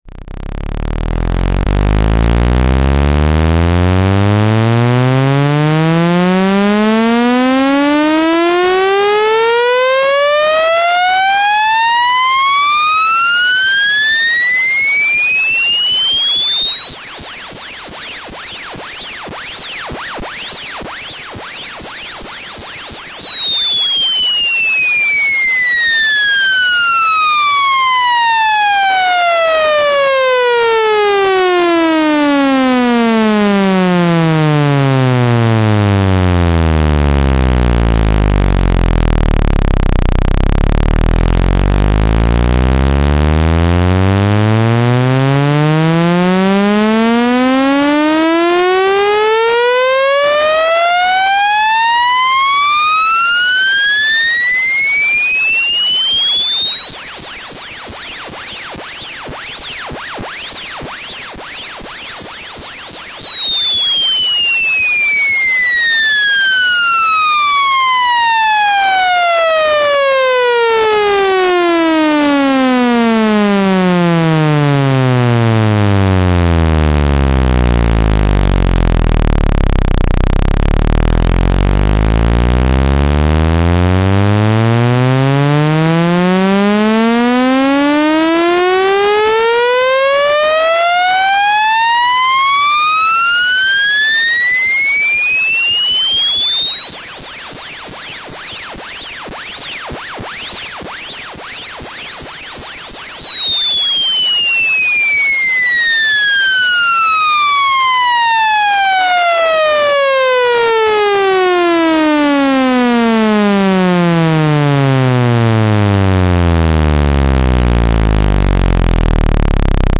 Clean Speaker Sound Sound Effects Free Download